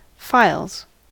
files: Wikimedia Commons US English Pronunciations
En-us-files.WAV